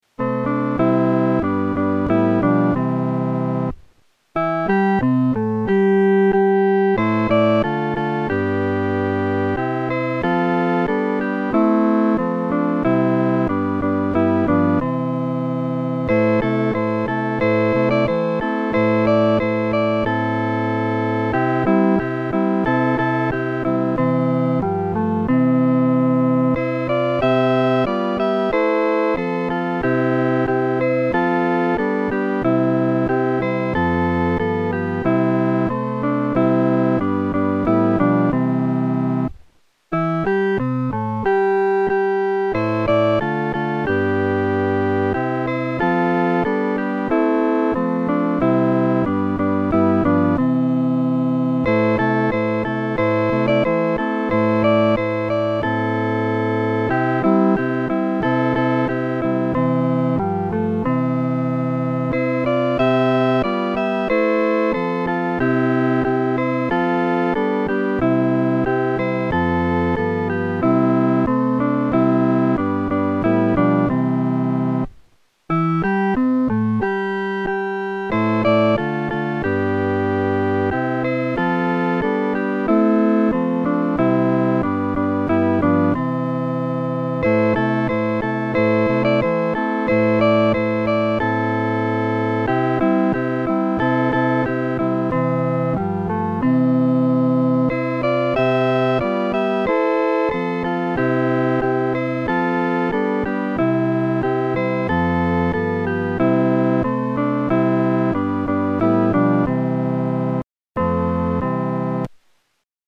伴奏
四声
本首圣诗由网上圣诗班录制
诗班在练习这首诗歌时，要清楚这首诗歌的音乐表情是欢庆地。